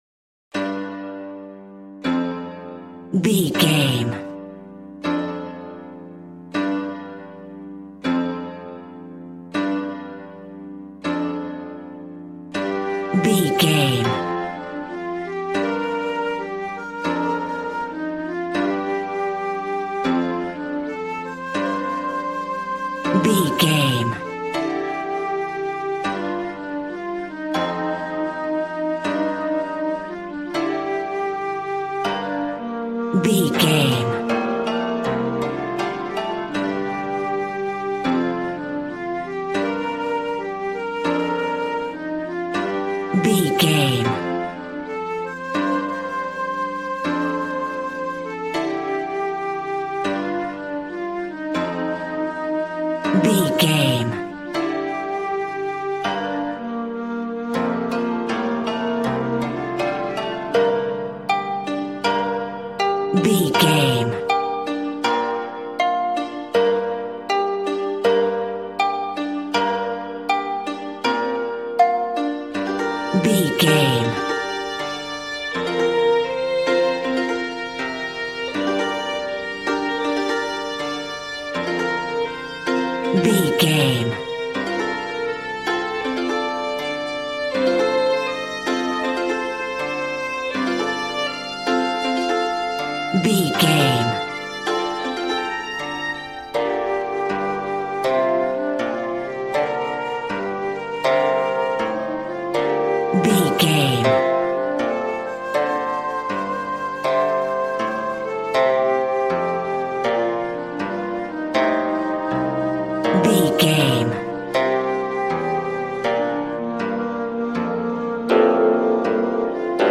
Aeolian/Minor
happy
bouncy
conga